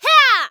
人声采集素材